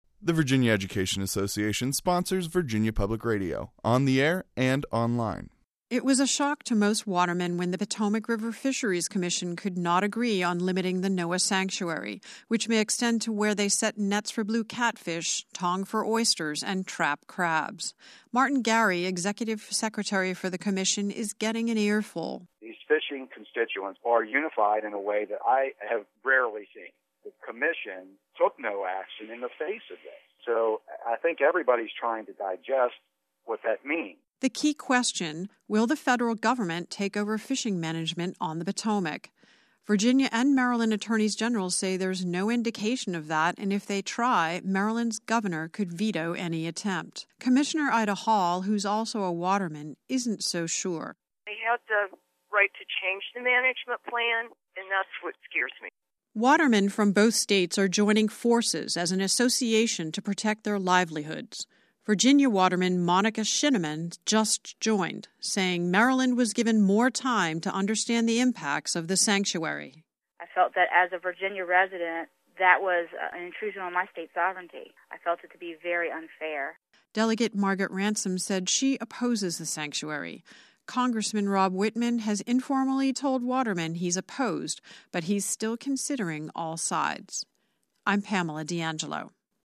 Debate Over Fishing Rights Remain for Virginia Watermen | Virginia Public Radio